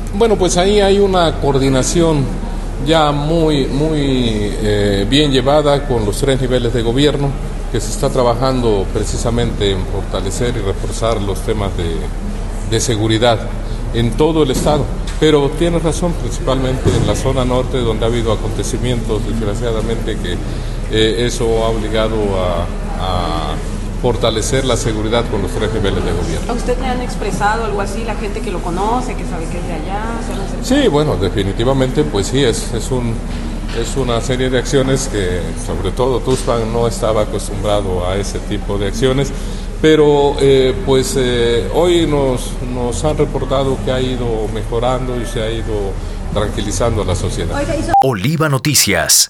En entrevista, afirmó que ante el aumento de los cuerpos de seguridad la sociedad ya esta tranquila.
21_jos_manuel_pozos_castro.wav